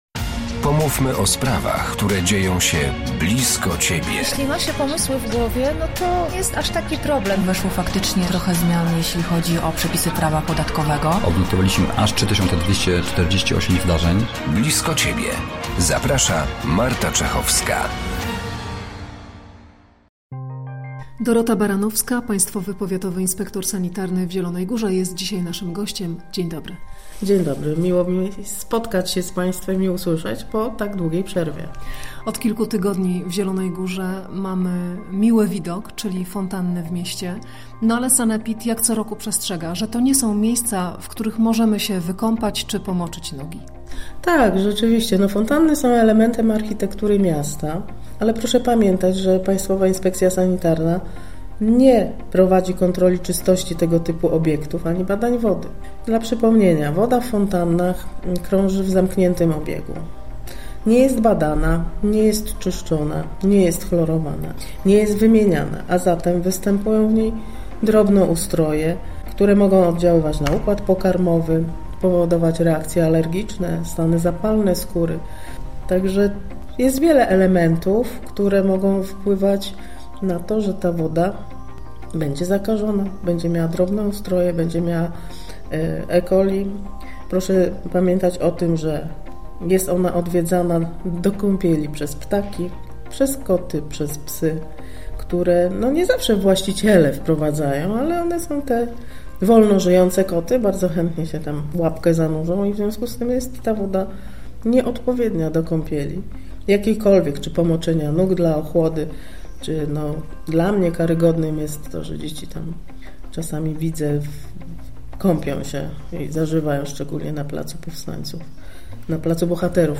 Dzisiaj o tym rozmowa